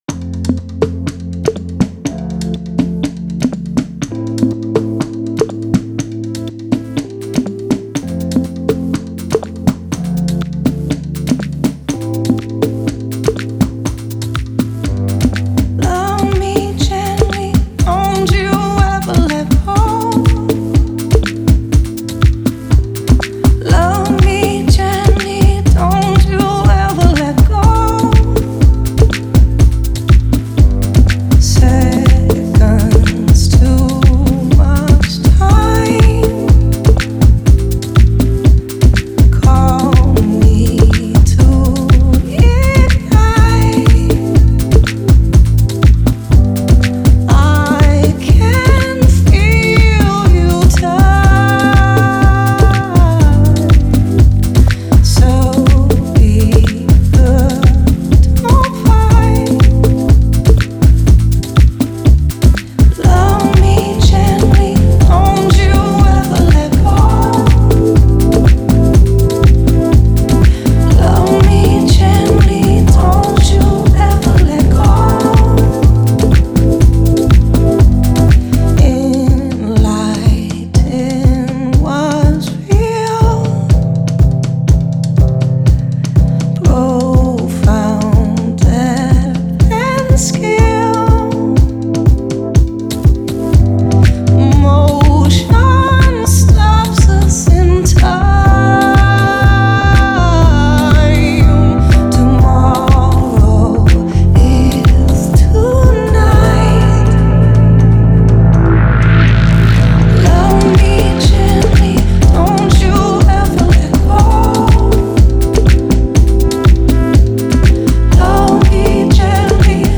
Additional keys (including outro solo)